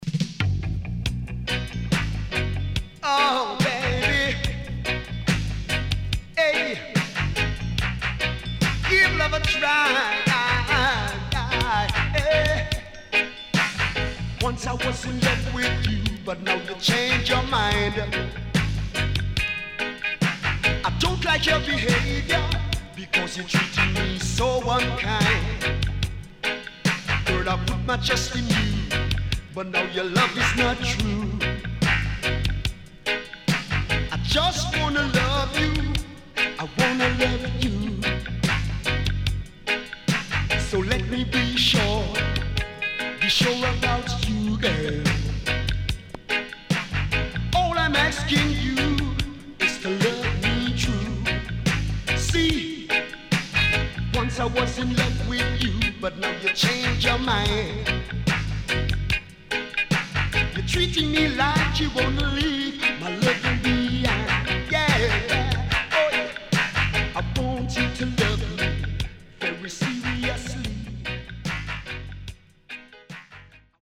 Dancehall Classic
SIDE A:少しチリノイズ入りますが良好です。